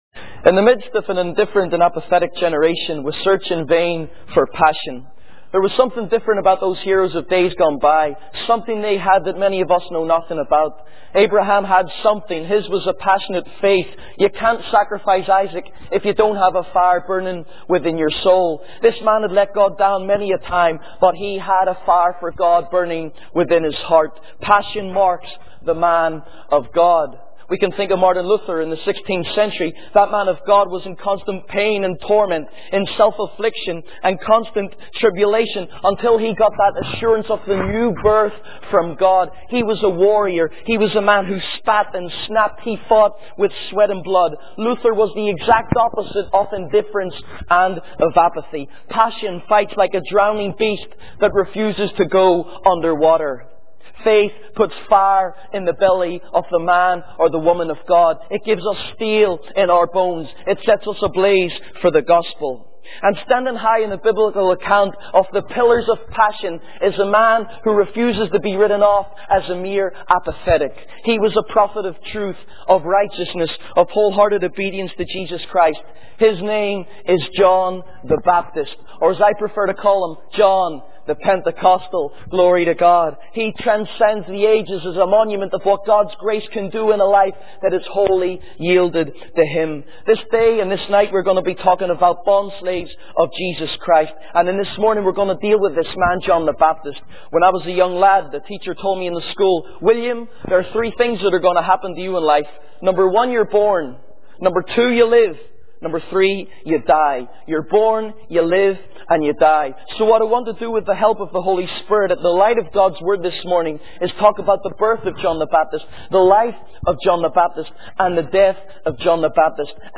In this sermon, the speaker emphasizes the importance of being obedient to God. He highlights the example of John the Baptist, who preached in the wilderness without any social life or security.